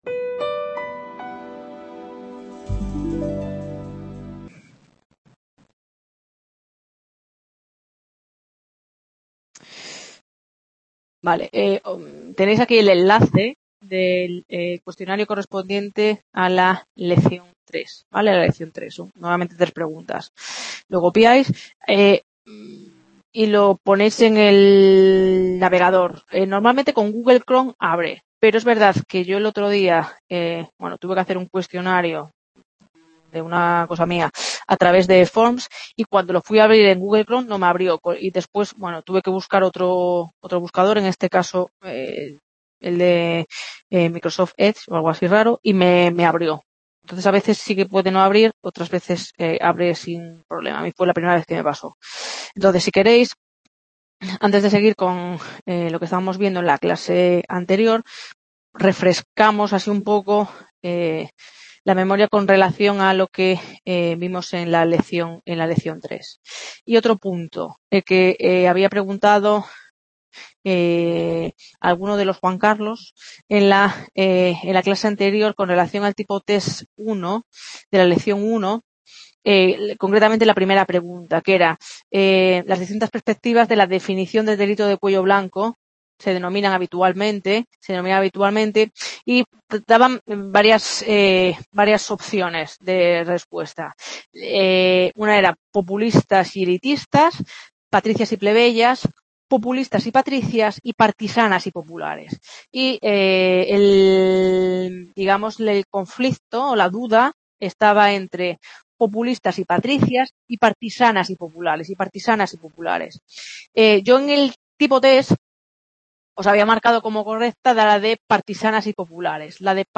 Lección 4